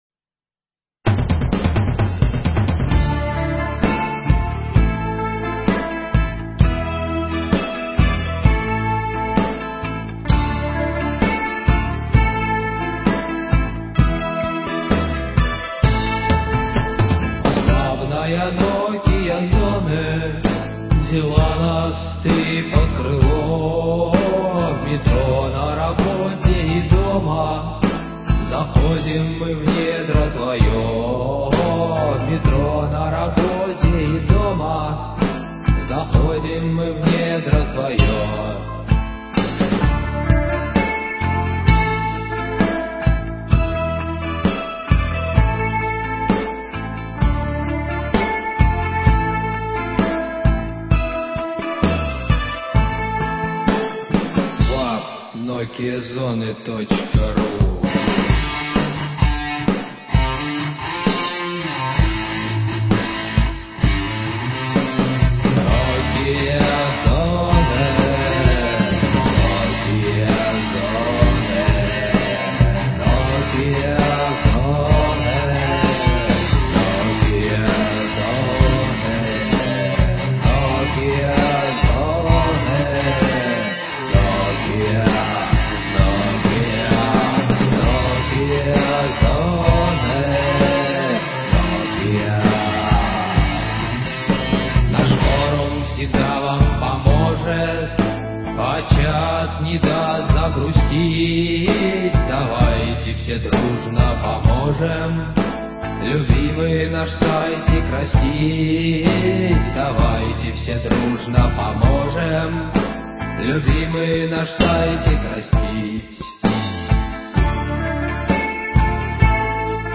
Песня про/для NokiaZone. Demo версия.